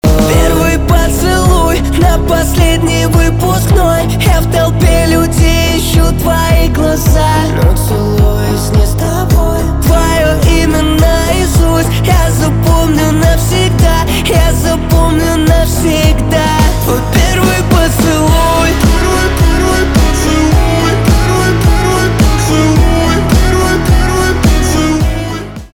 поп
чувственные , романтические
гитара